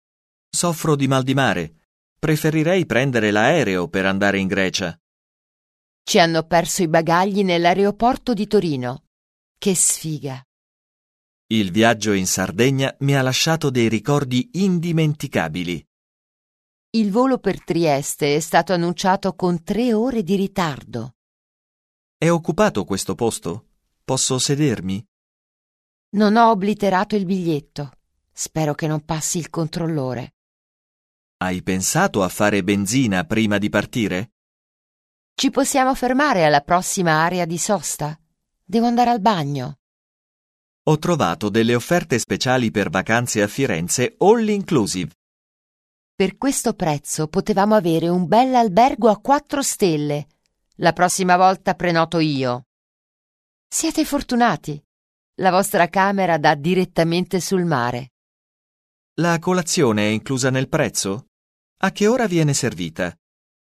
Un peu de conversation - Les moyens de transport, l'hébergement